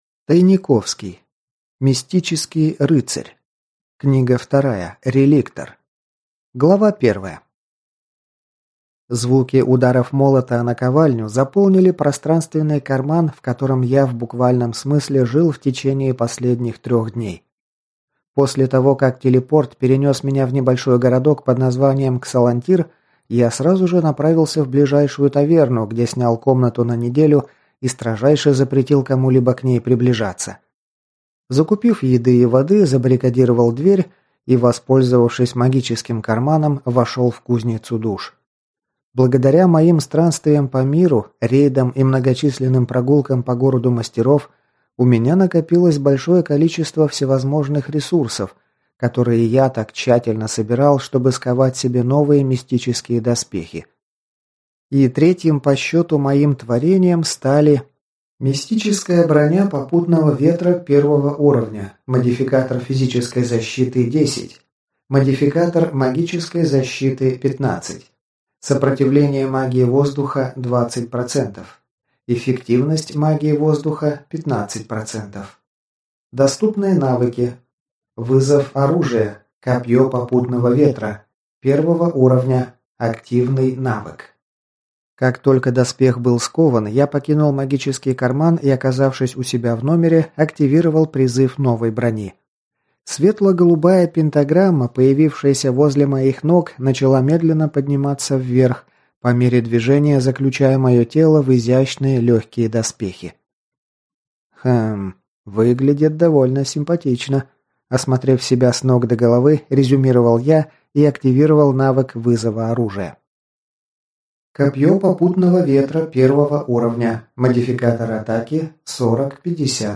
Аудиокнига Реликтор | Библиотека аудиокниг